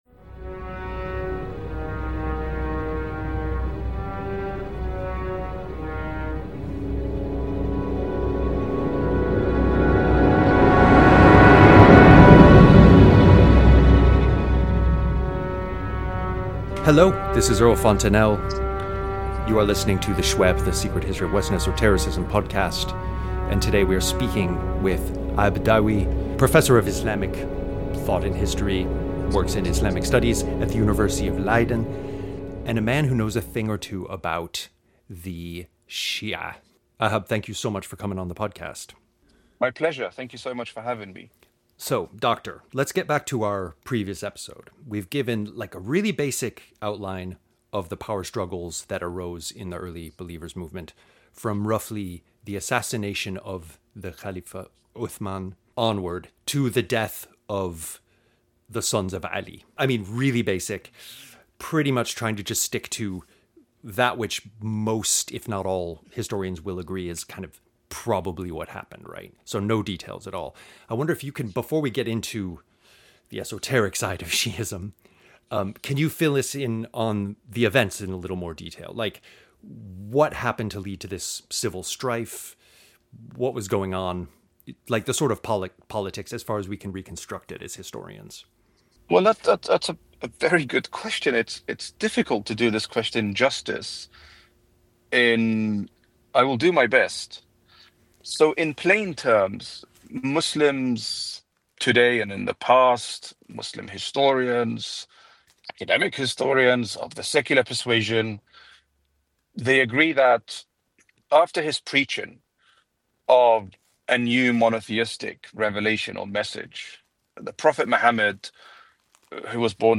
Interview Bio